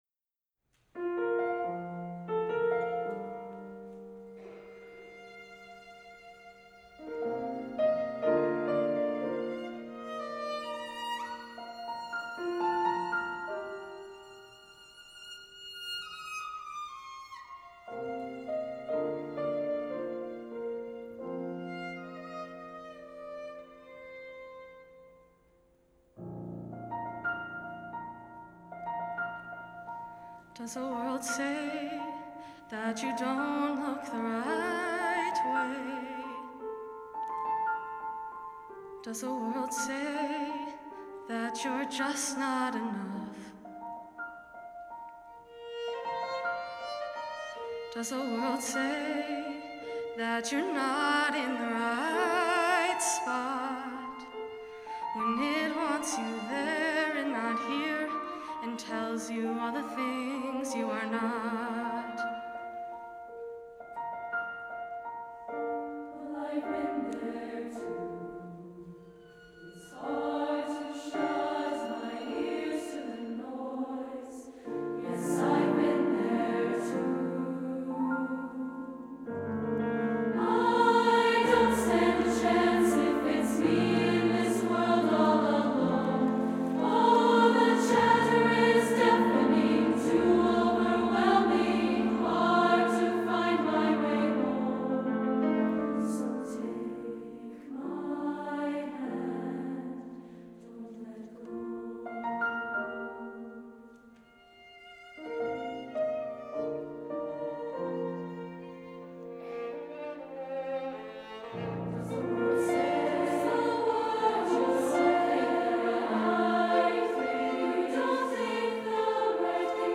Voicing: SA, SSA or SSAA and Piano